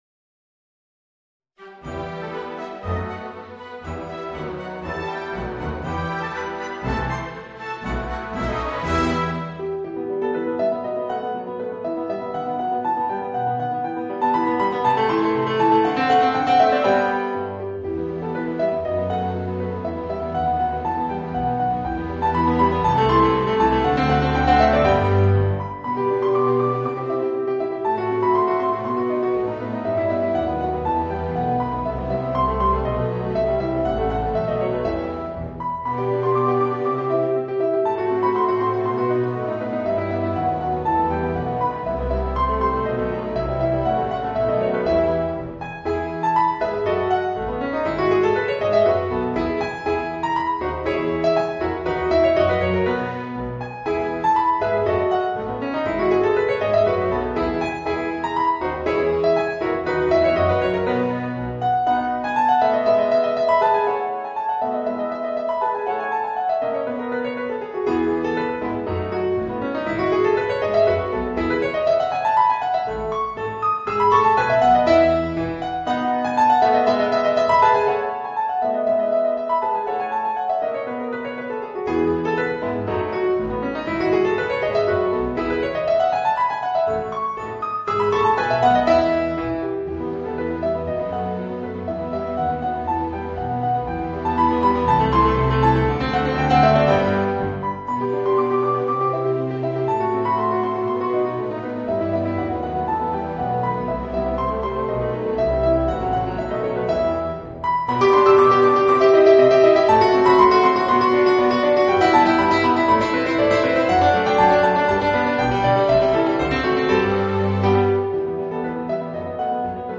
Voicing: Piano and Orchestra